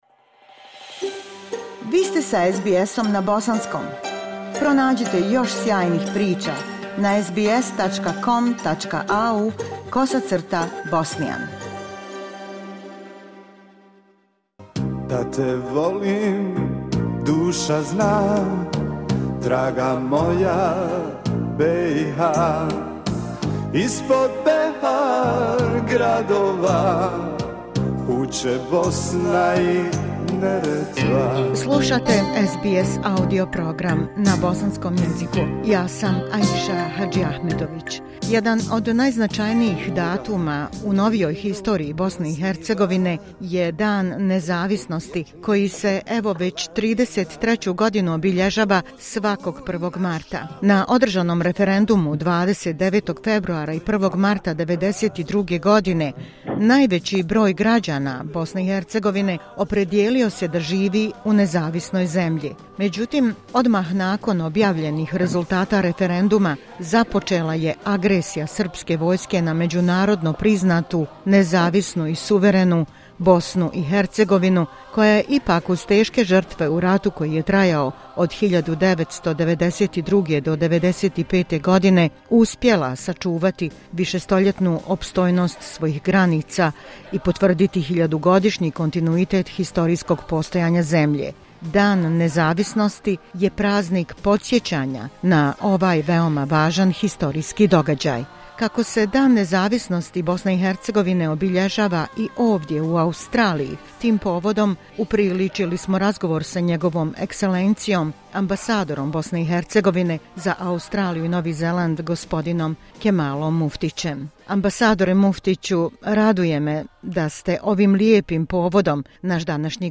U povodu obilježavanja Dana nezavisnosti Bosne i Hercegovine u Australiji razgovarali smo sa ambasadorom Kemalom Muftićem, koji govori o planiranom programu u ambasadi, značaju ove zajedničke kuće u Canberri za sve Bosance i Hercegovce, i poruci koju je uputio građanima čestitajući im najveći državni praznik.